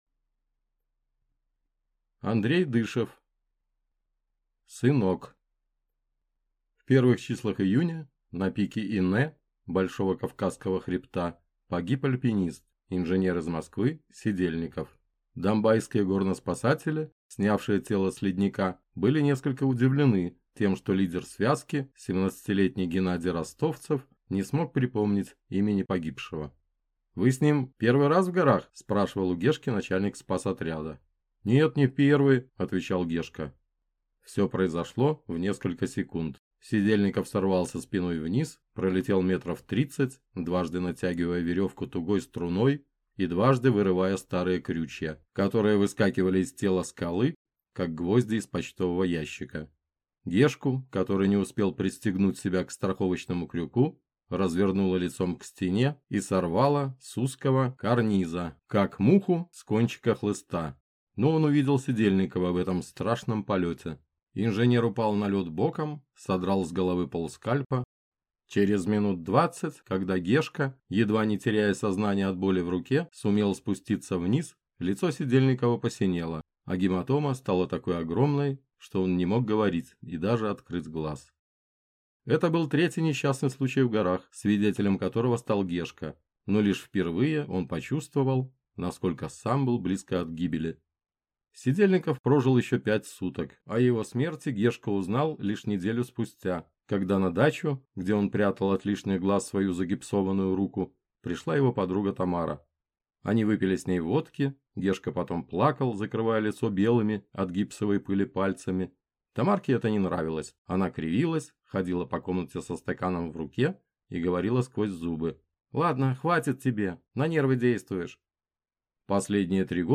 Аудиокнига Сынок | Библиотека аудиокниг